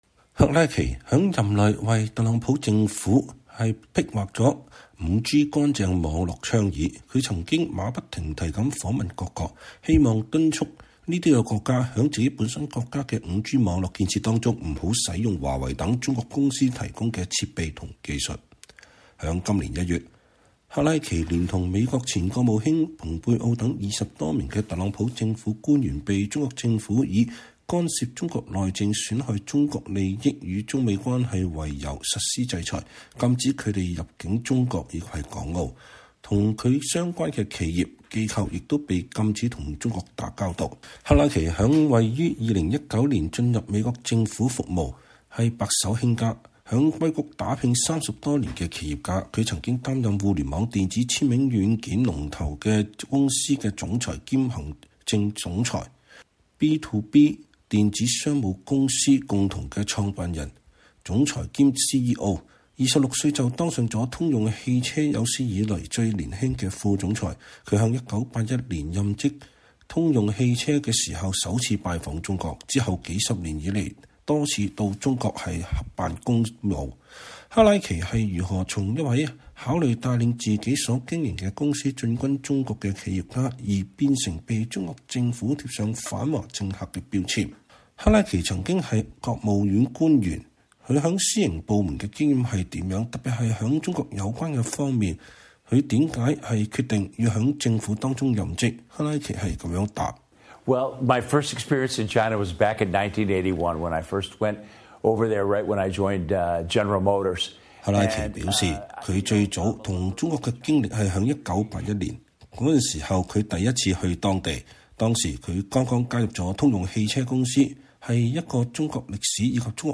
專訪前美國國務次卿克拉奇: 我絕不會向習近平屈膝